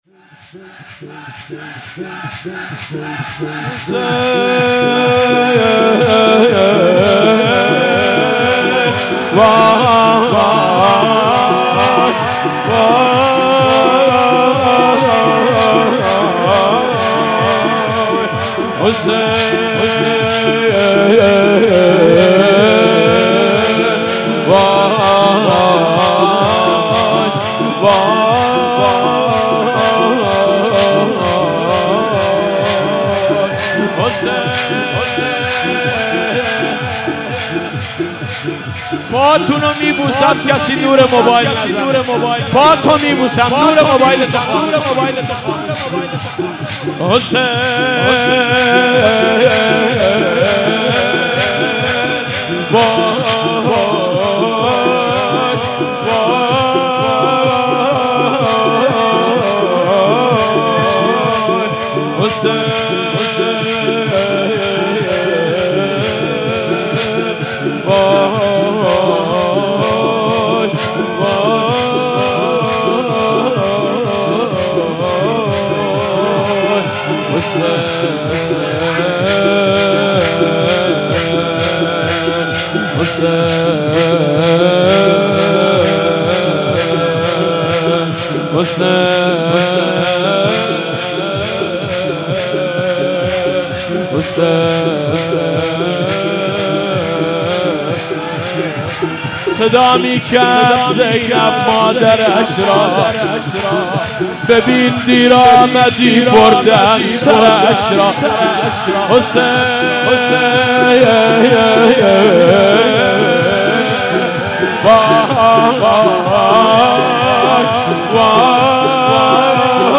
هیئت خدام العباس(ع)اهواز
مراسم هفتگی/22آذر97